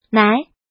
怎么读
[ nái ]